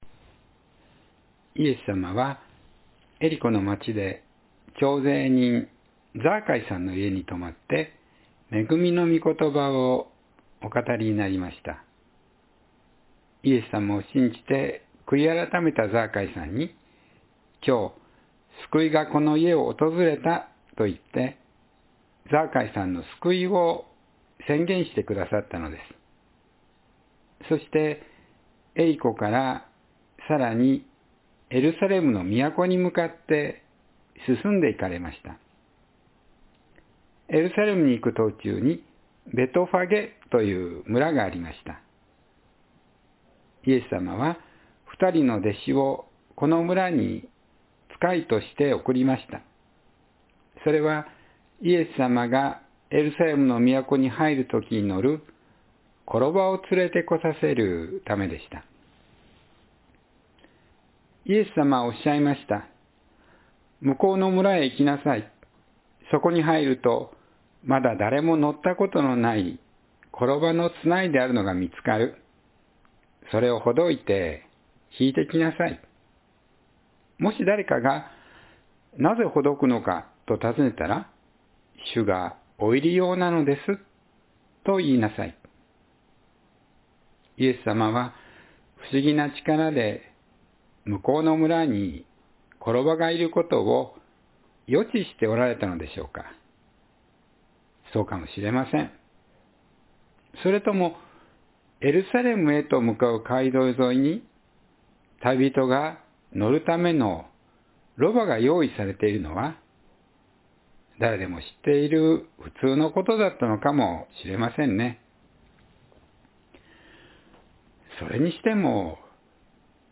イエスさまは平和の王（2026年3月8日・子ども説教）